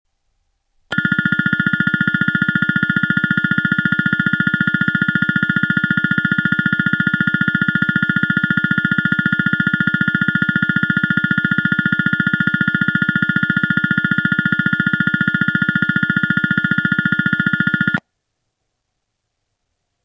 接近寺にカラカラベルが鳴るのみです。放送はありません。
上下線共に進入列車と被り、上り線は車両停止位置と被ります。
スピーカー TOA丸型
接近ベル ベル PCM